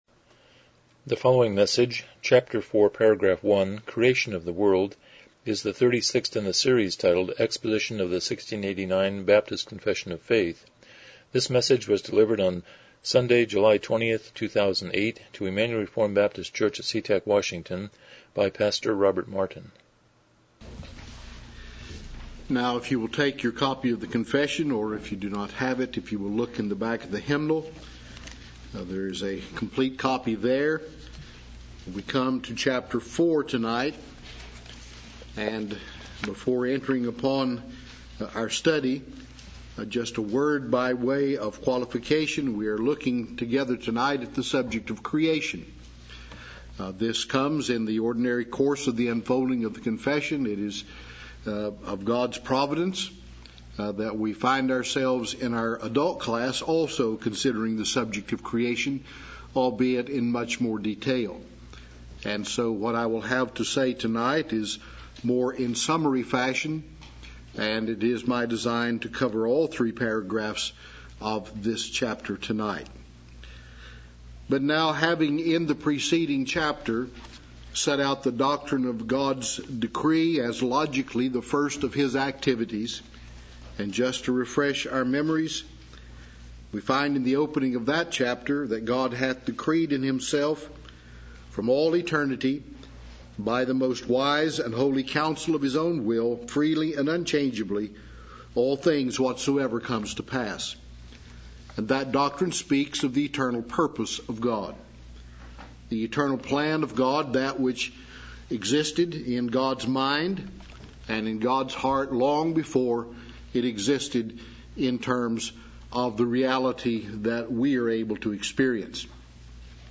1689 Confession of Faith Service Type: Evening Worship « 52 Review #10